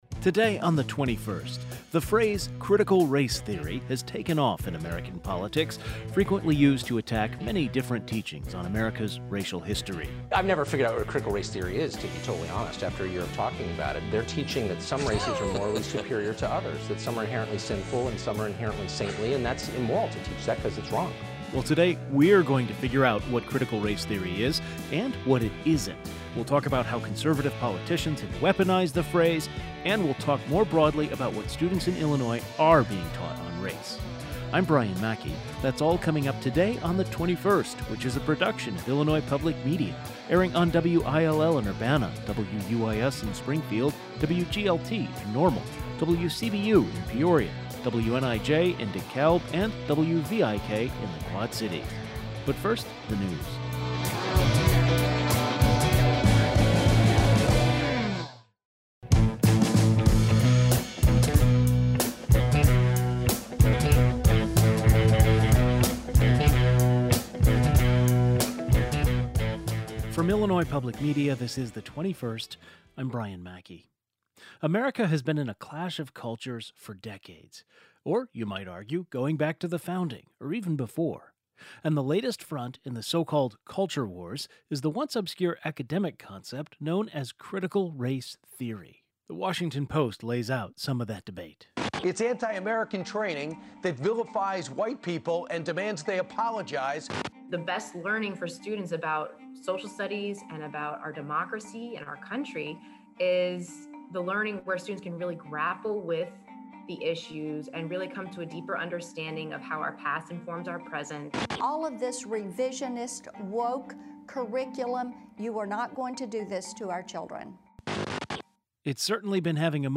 Today we spent our show understanding what Critical Race Theory is, why it’s become so weaponized and what examining systemic racism in a classroom setting looks like. To talk about it, we were joined by a professor of information sciences, a professor of education who teaches Critical Race Theory, a professor from NIU's Center for Black Studies and a public policy reporter.